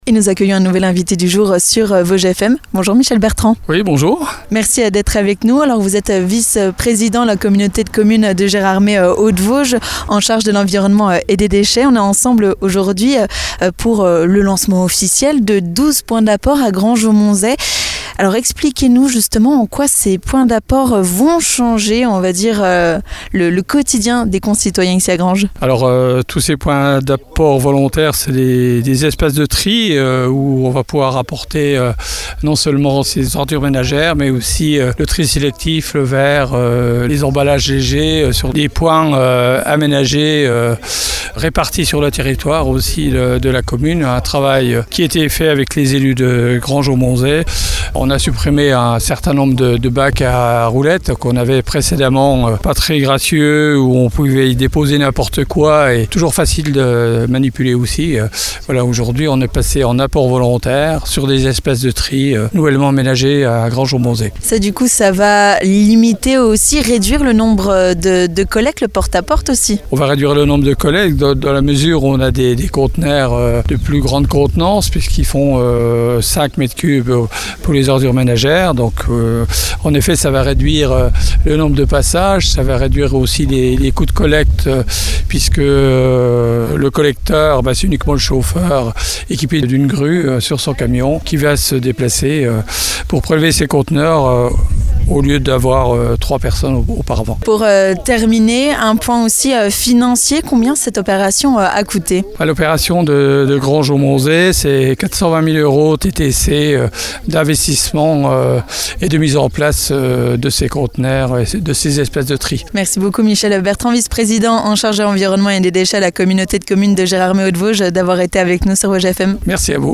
3. L'invité du jour
Le point avec notre invité du jour, Michel Bertrand, vice-président de la Communauté de communes de Gérardmer Hautes-Vosges en charge de l'environnement et des déchets.